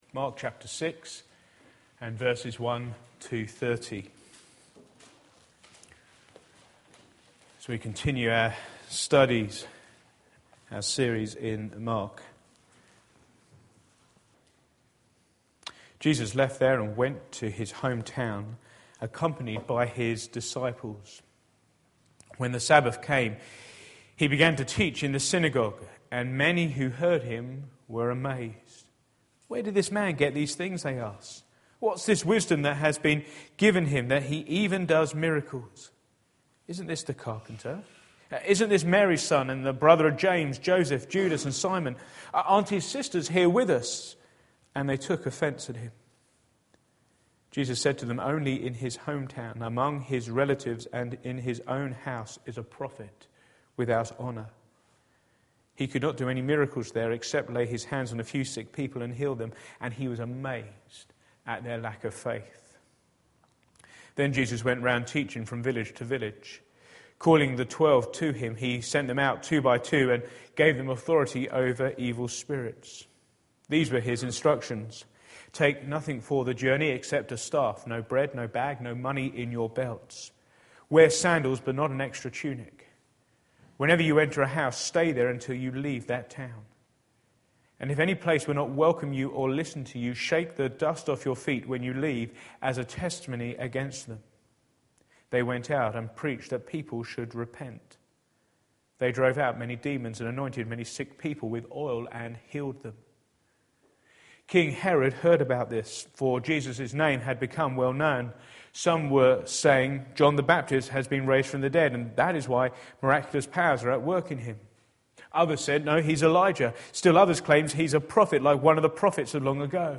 Back to Sermons Rejecting the Kingdom